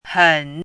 怎么读
hěn
hen3.mp3